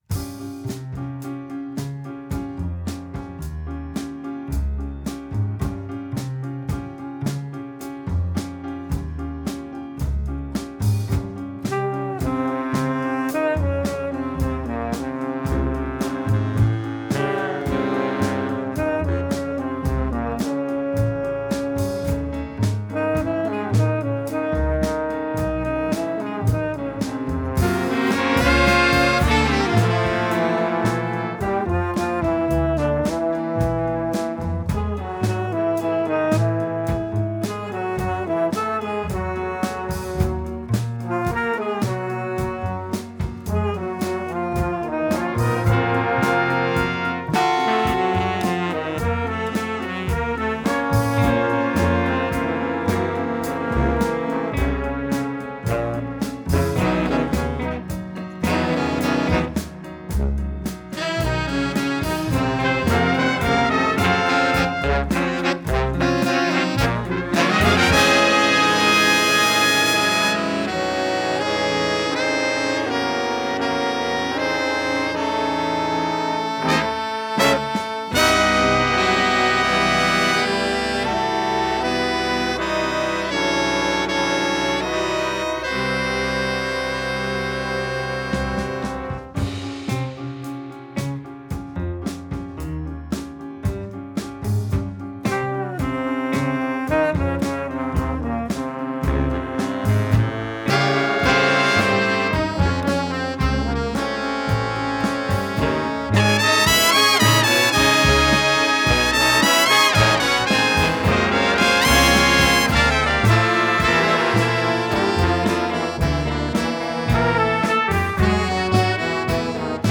under live conditions.